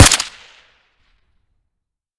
svd_shoot_s.ogg